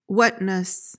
PRONUNCIATION: (WAT-nis) MEANING: noun: That which constitutes the fundamental nature of a thing: the essence or inherent quality.